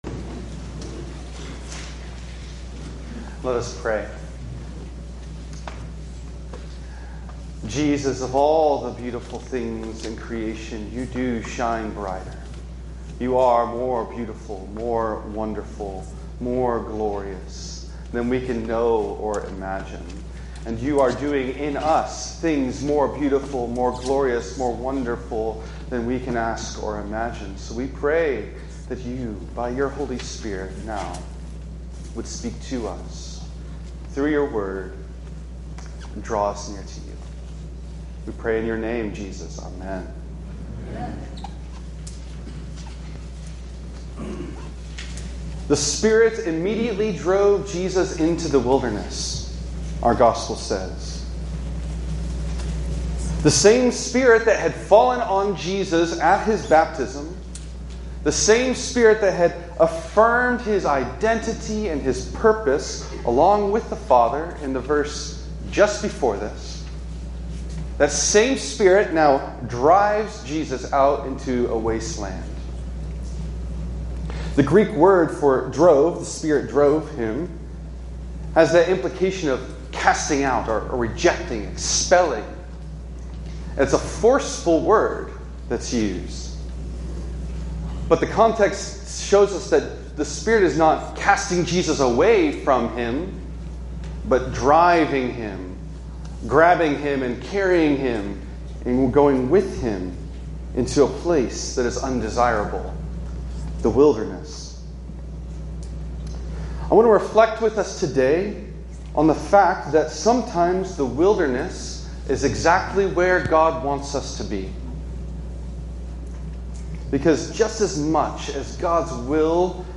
On the first Sunday in Lent